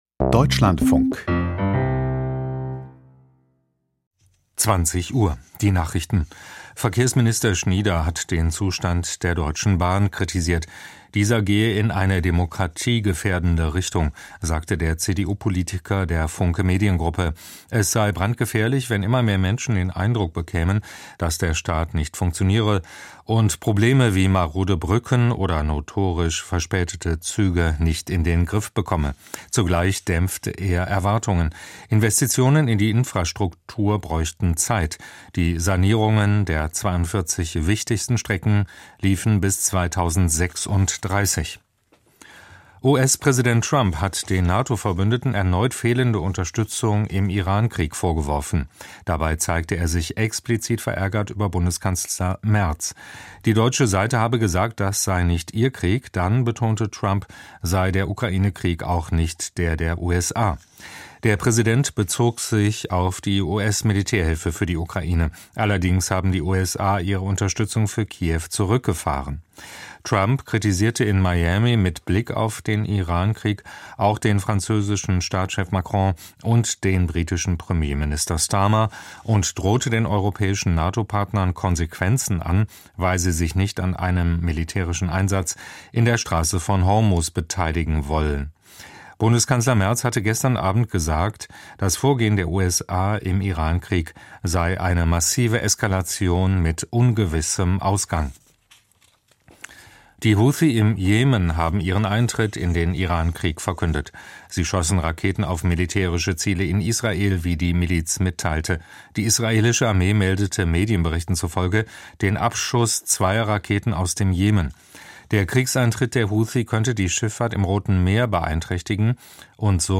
Die Nachrichten vom 28.03.2026, 20:00 Uhr